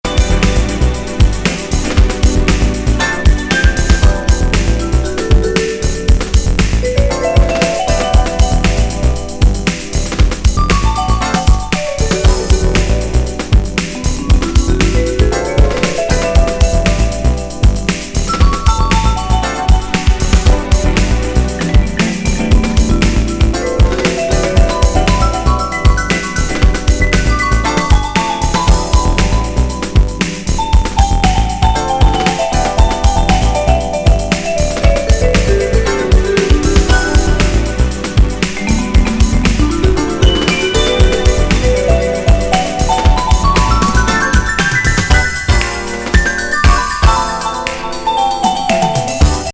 極上エレクトリック・フュージョン!